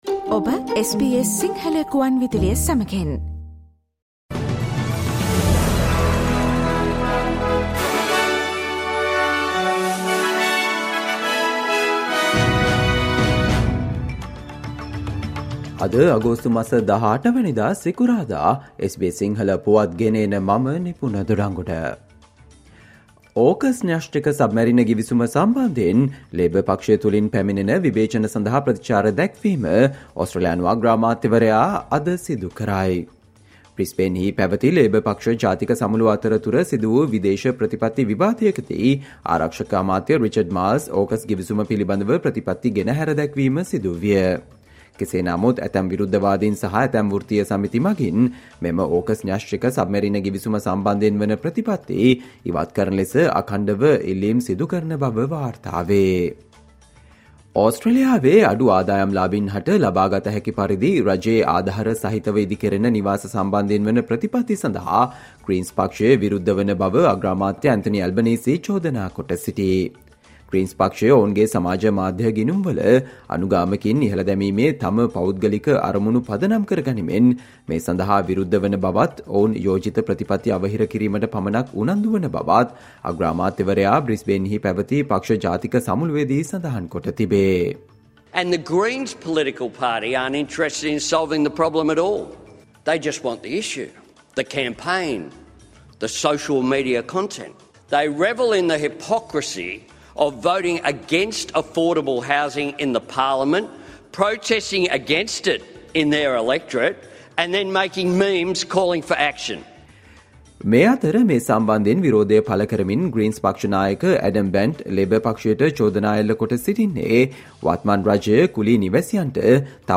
Australia news in Sinhala, foreign and sports news in brief - listen, today - Friday 18 July 2023 SBS Radio News